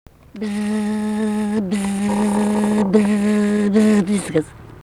smulkieji žanrai